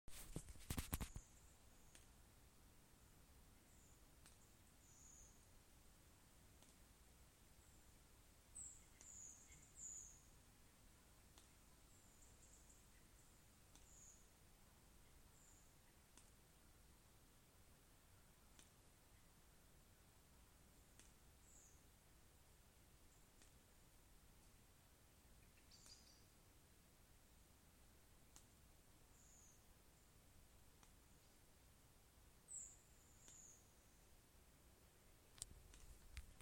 Birds -> Thrushes ->
Redwing, Turdus iliacus